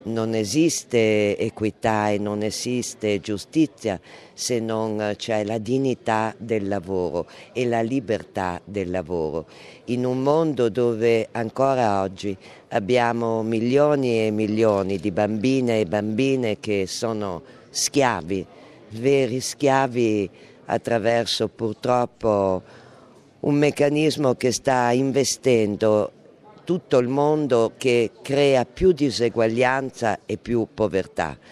Ascolta l'intervista ad Annamaria Furlan: